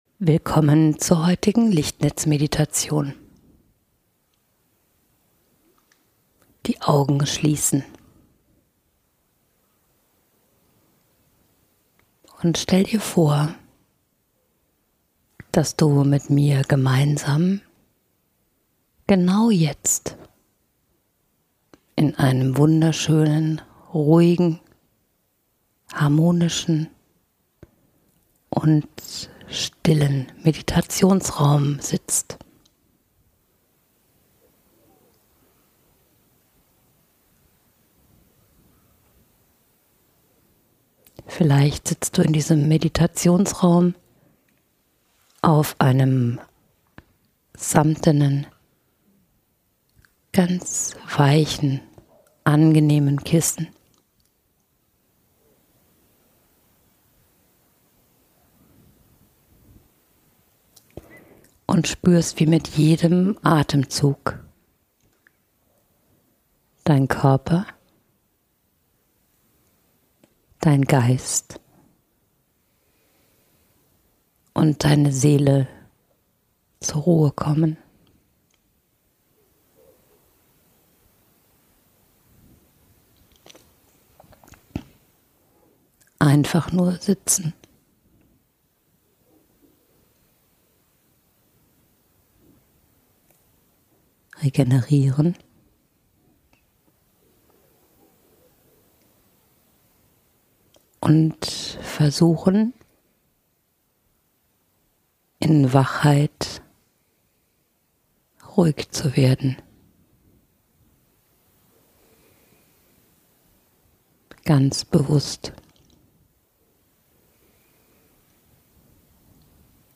Mit ganz wenigen Worten kannst du hier tiefe Ruhe, inneren Frieden, Stille und Ordnung in dir finden und in die Magie und Wirkkraft der Einfachheit eintauchen.